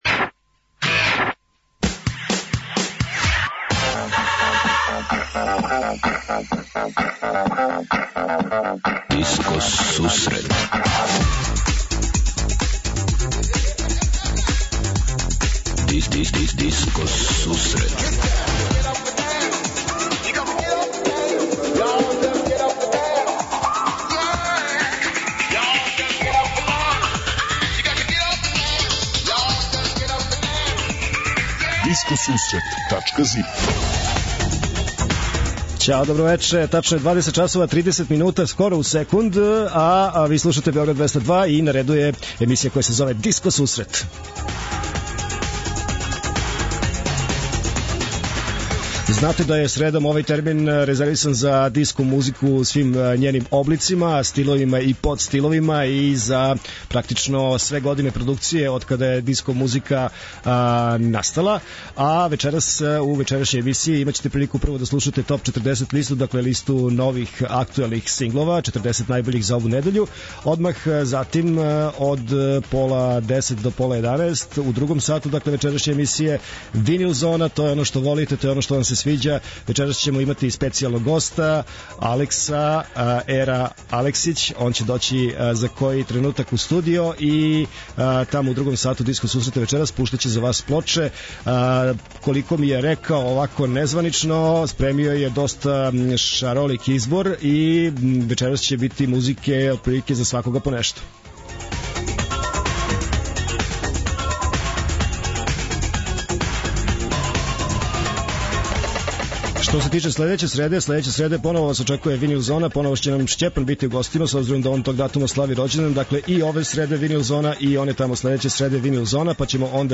преузми : 26.98 MB Discoteca+ Autor: Београд 202 Discoteca+ је емисија посвећена најновијој и оригиналној диско музици у широком смислу, укључујући све стилске утицаје других музичких праваца - фанк, соул, РнБ, итало-диско, денс, поп.
21:30 Винил Зона Слушаоци, пријатељи и уредници Диско Сусрета за вас пуштају музику са грамофонских плоча.